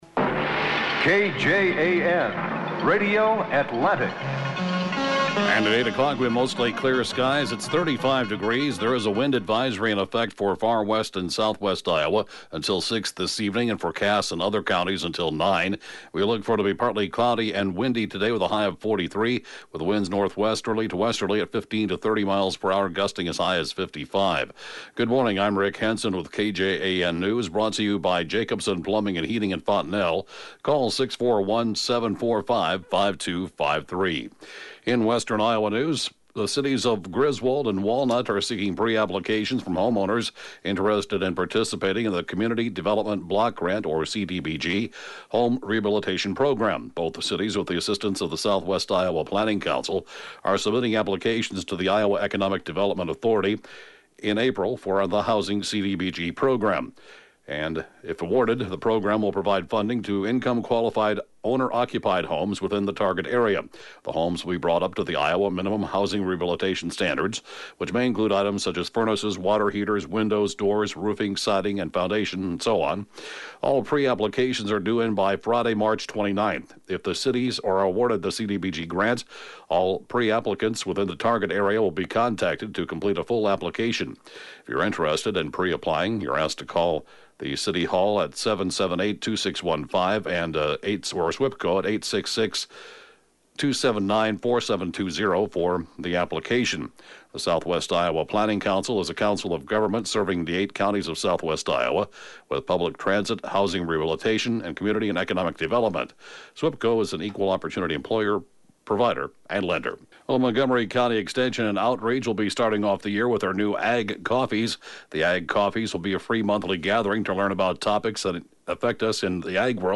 (Podcast) KJAN Morning News & Funeral report, 2/14/2019